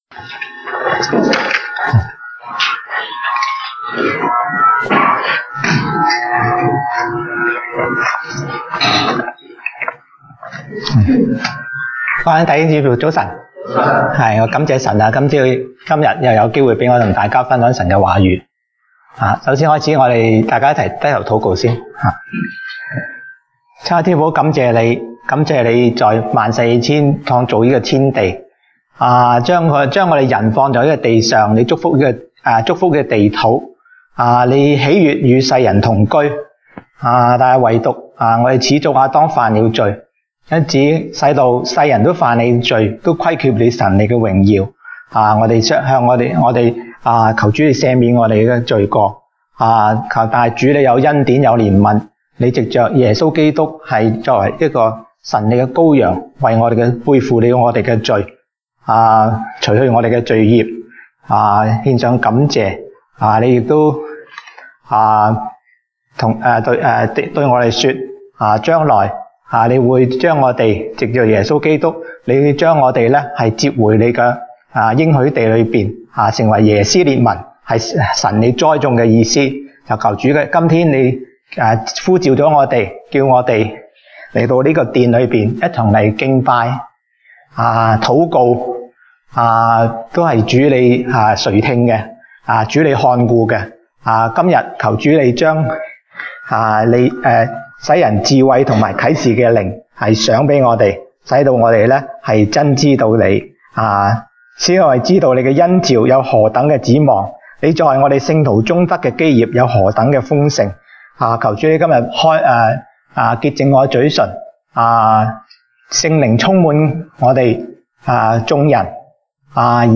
東北堂證道 (粵語) North Side: 神的禮物